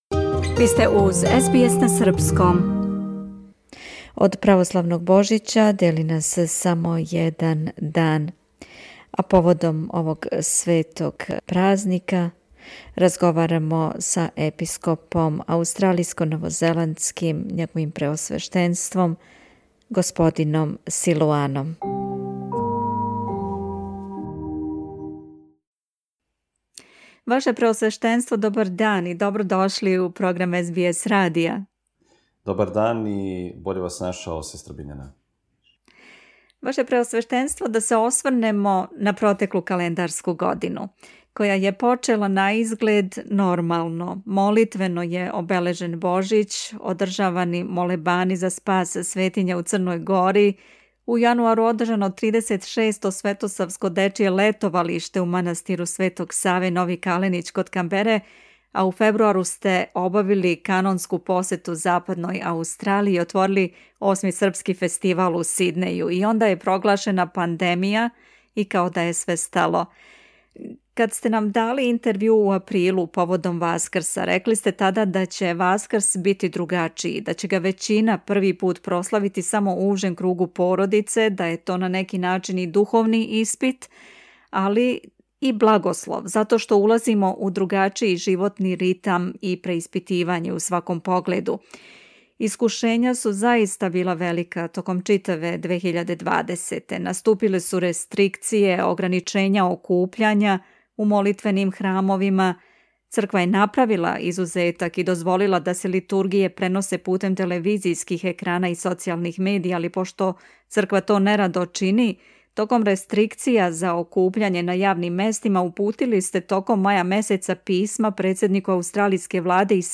Поводом православног Божића чија прослава почиње сутра на Бадњи дан и наставља се на сам дан Божића, у четвртак, и наредних дана када се обележавају Сабор Пресвете Богородице и Свети архиђакон Стефан, разговарали смо са епископом аустралијско-новозеландским г. Силуаном.